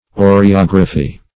Search Result for " oreography" : The Collaborative International Dictionary of English v.0.48: Oreography \O`re*og"ra*phy\, n. [Gr.